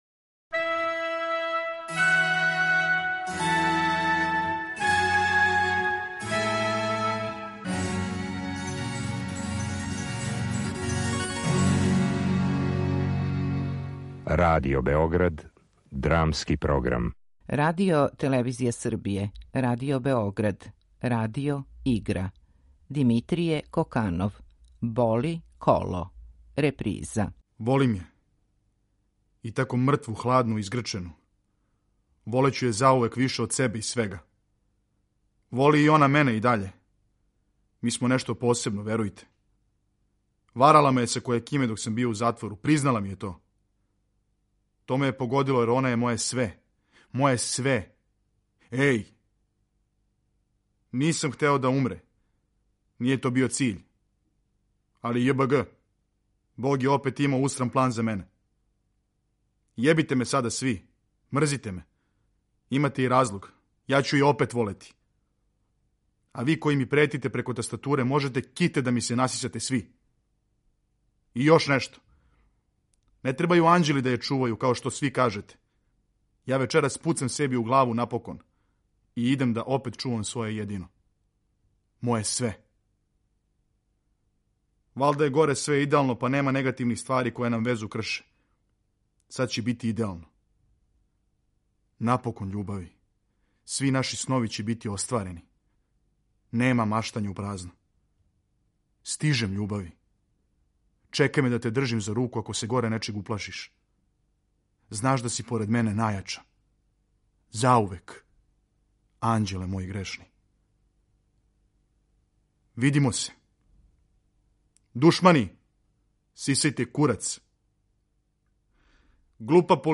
Радио-игра
drama.mp3